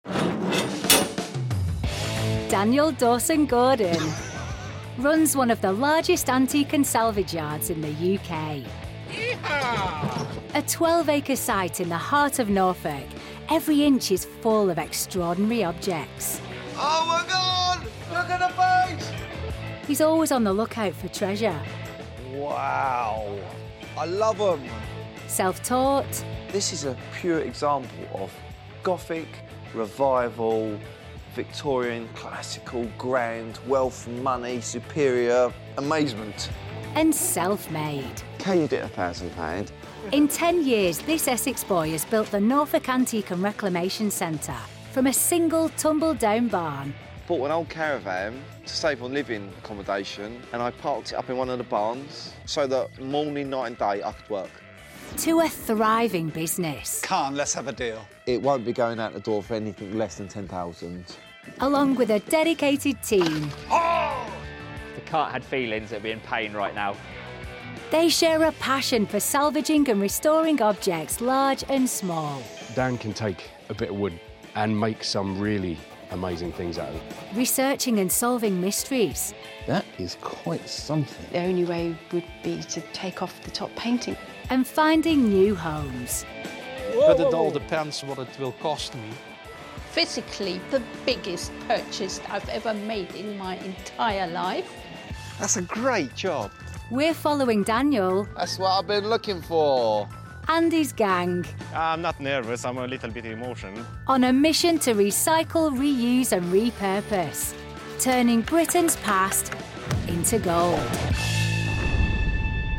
Rapide, fiable et naturellement conversationnelle, elle offre une voix off professionnelle qui captive et captive.
* Cabine de son spécialement conçue, isolée et traitée acoustiquement
* Micro et protection anti-pop Rode NT1-A